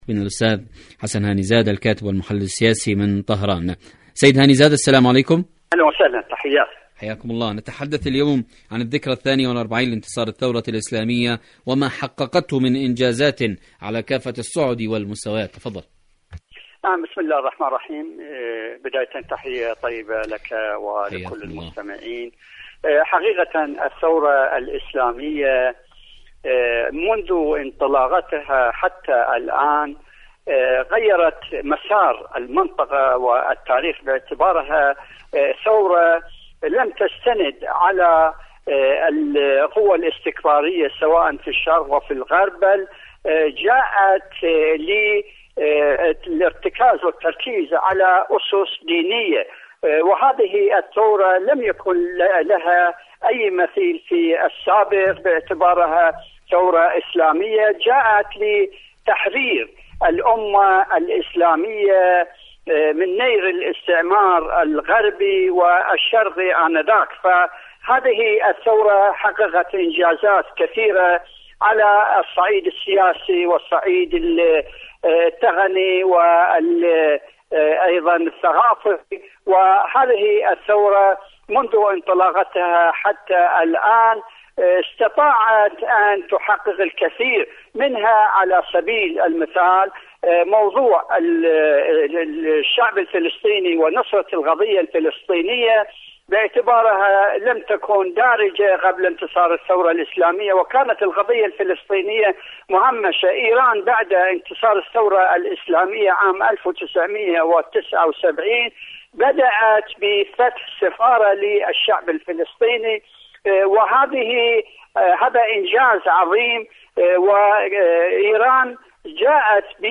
إذاعة طهران-ألو طهران: مقابلة إذاعية